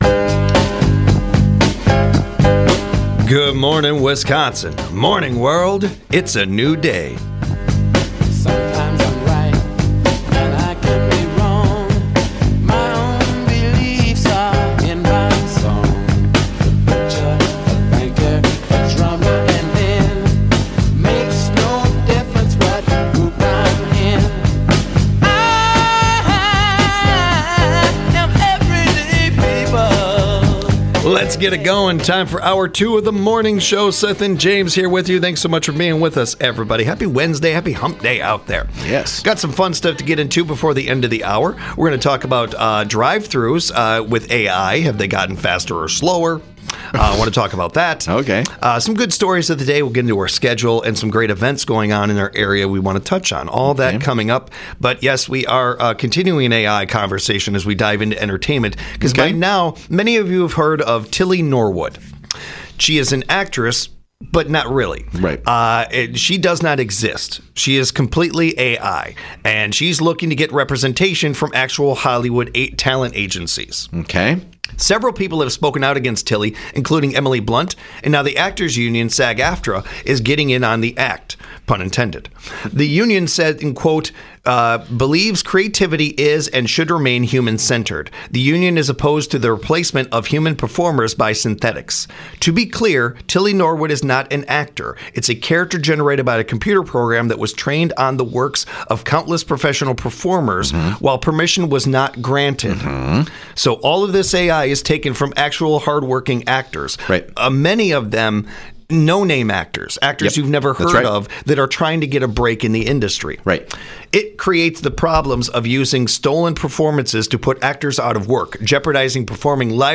A new survey about overthinking came out, so the guys discussed those results.